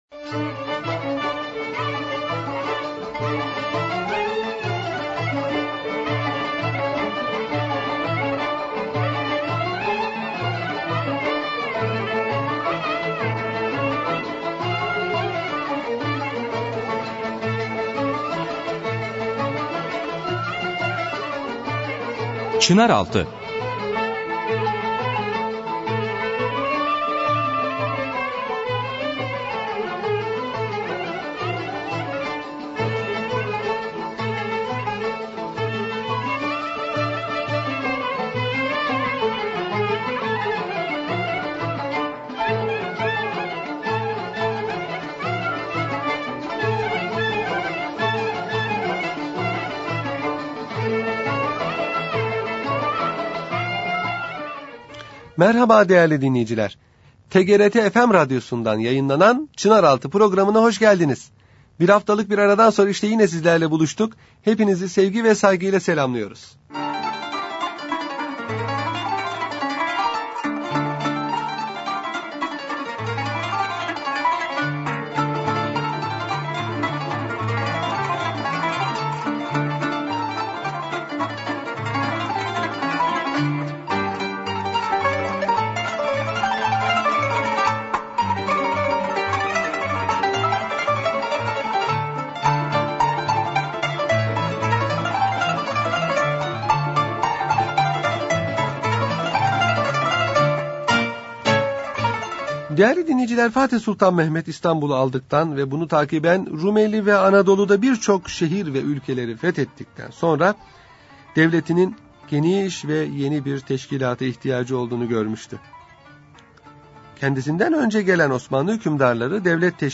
Radyo Programi - Fatih Kanunnamesi - Anibal